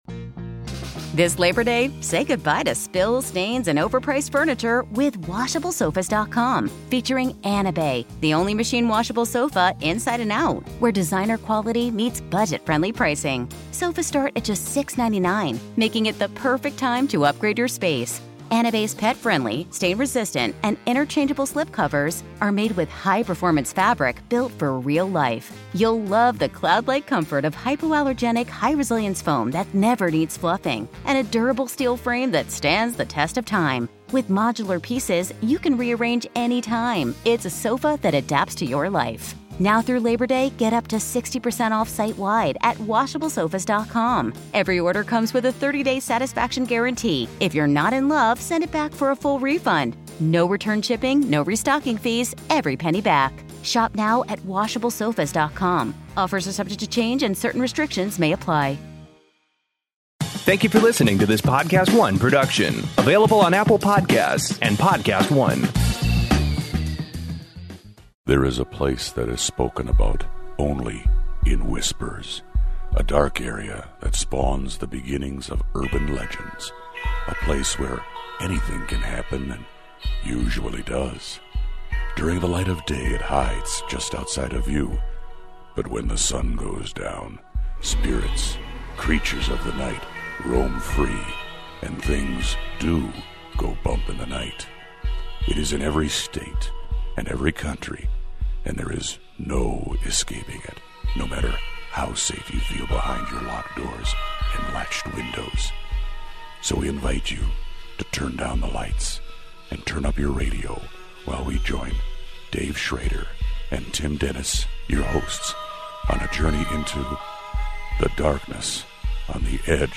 Strange Supernatural News and YOUR stories on the best in paranormal talk radio. Haunted Bars, Houses and Violent Spirits are freaking out all over the world and fill the show today in a week of veil thinning awesomeness PLUS your calls!